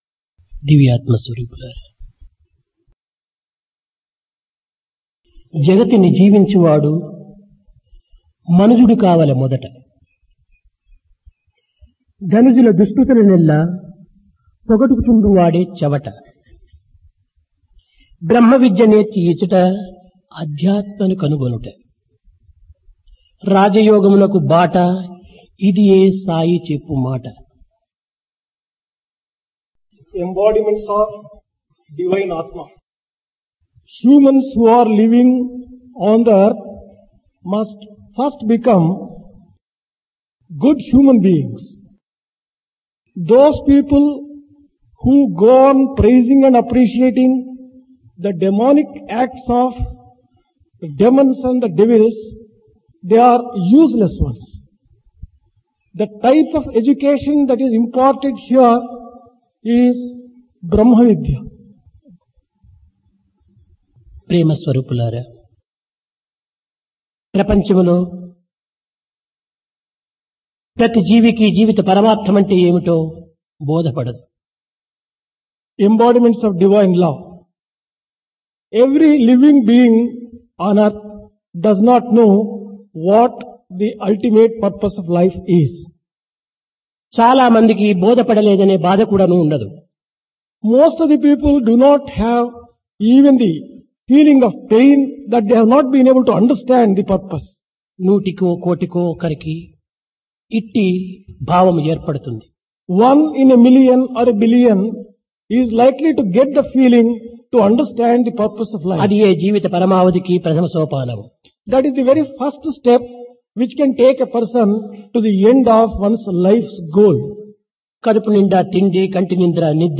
Divine Discourse to University Teachers and Students | Sri Sathya Sai Speaks
Place Prasanthi Nilayam